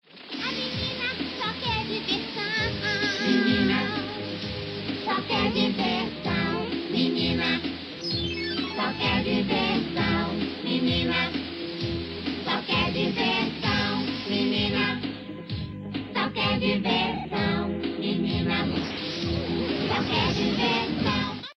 Music Sample
Fair use music sample